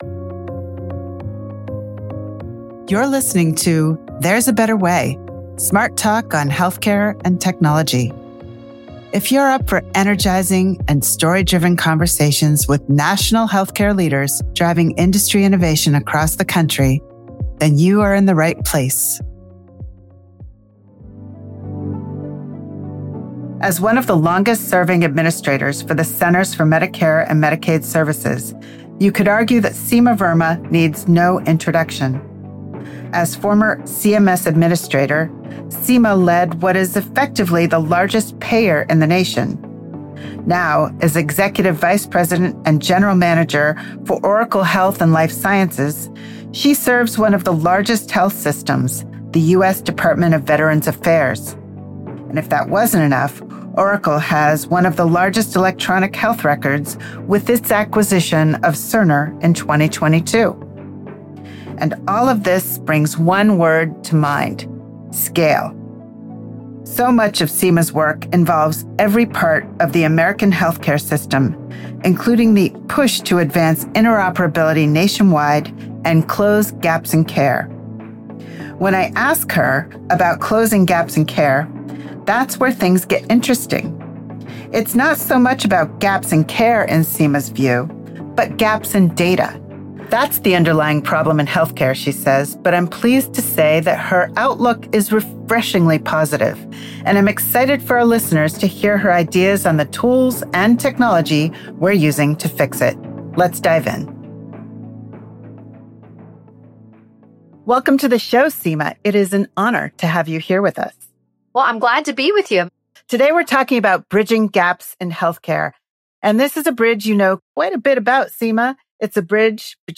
sits down with today’s most inspiring and innovative leaders in healthcare for in-depth and personal conversations. They’ll share their perspectives on the industry’s challenges and the efforts underway for navigating them.